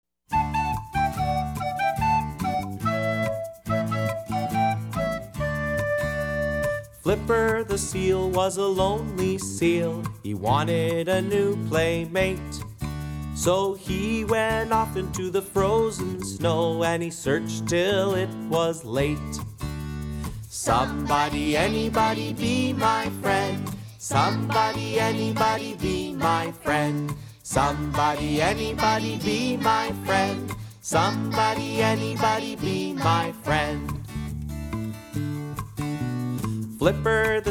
Animal Song Lyrics